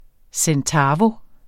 Udtale [ sεnˈtæːvo ]